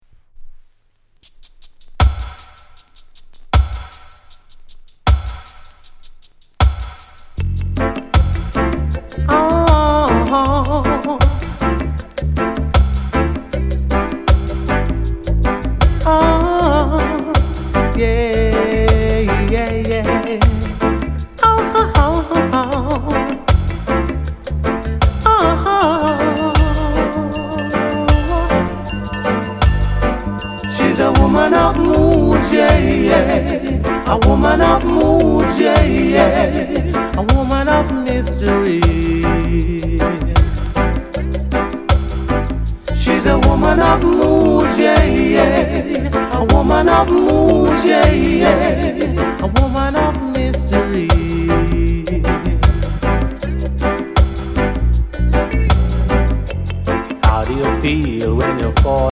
NICE LOVERS ROCK